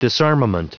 Prononciation du mot disarmament en anglais (fichier audio)
Prononciation du mot : disarmament